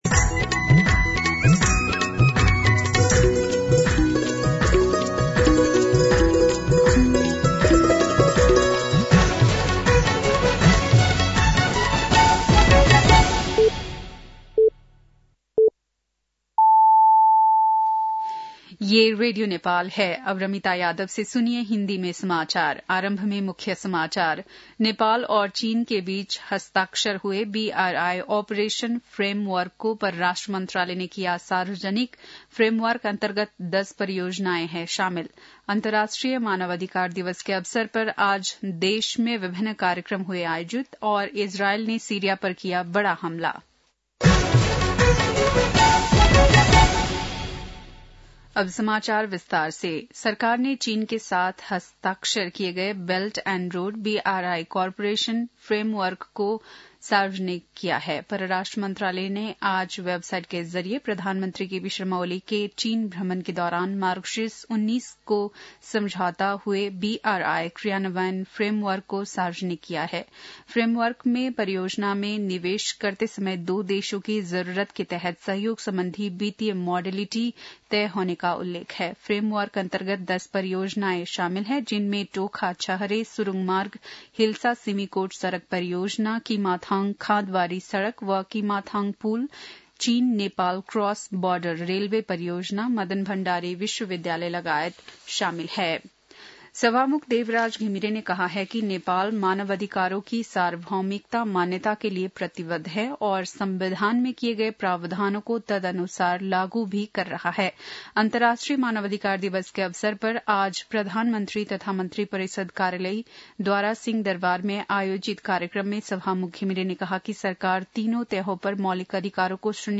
बेलुकी १० बजेको हिन्दी समाचार : २६ मंसिर , २०८१
10-PM-Hindi-News-8-25.mp3